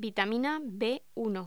Locución: Vitamina B1